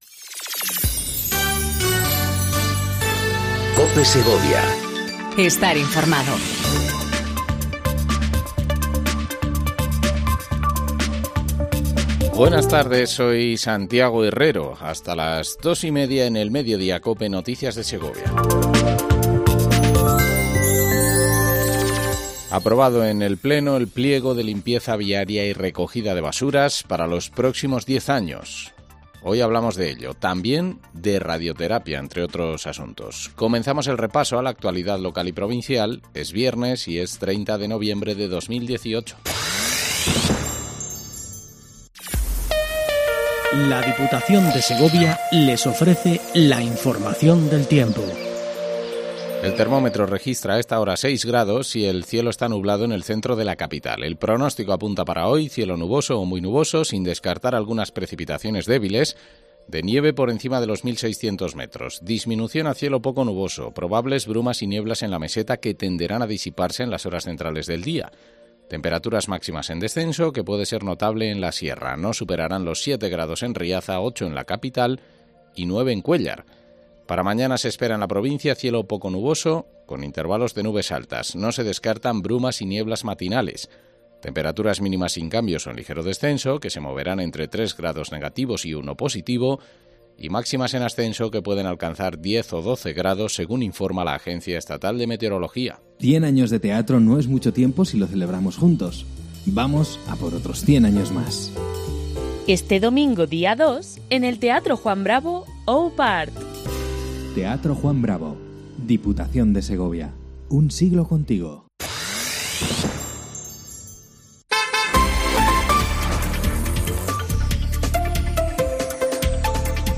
AUDIO: Repaso informativo a la actualidad local y provincial 30/11/18